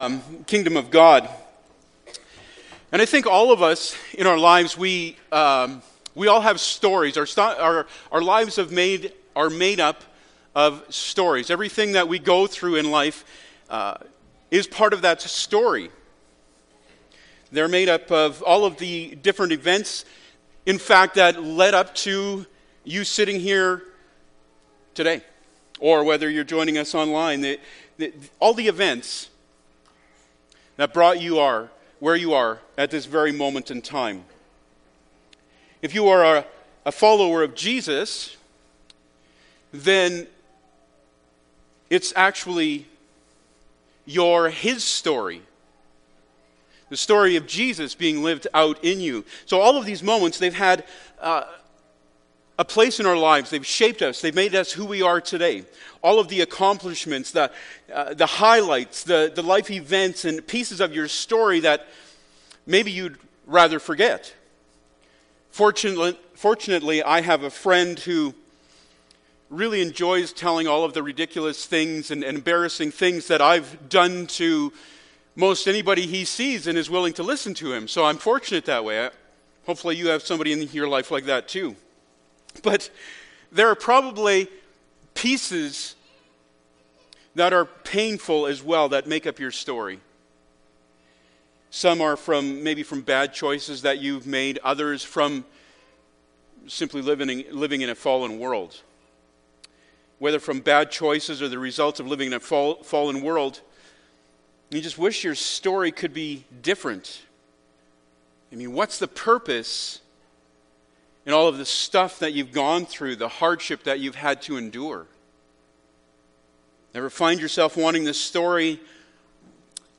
Colossians 3:1-17 Service Type: Sunday Morning Bible Text